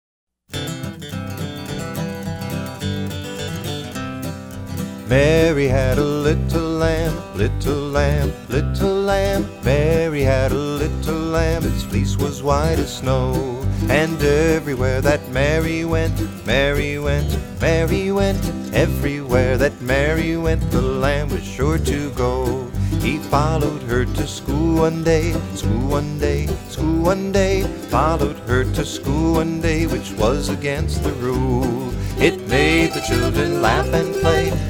This collection of folk song favorites